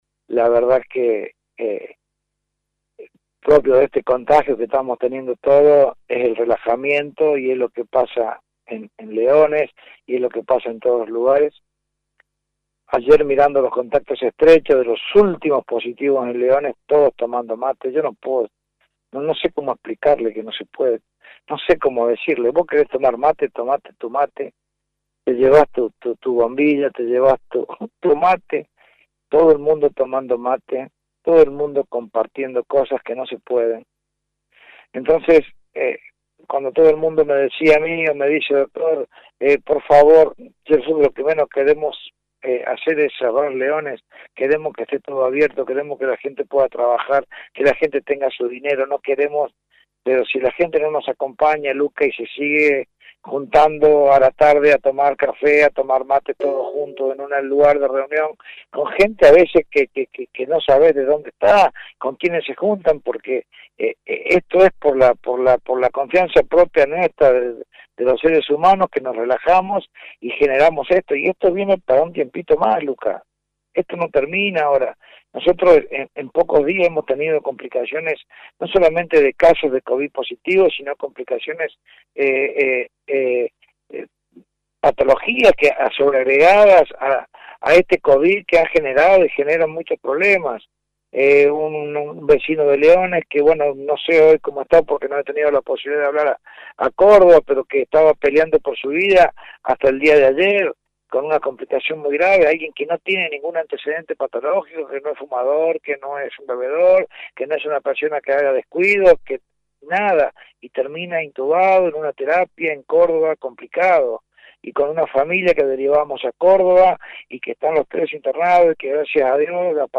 El intendente habló con La Mañana.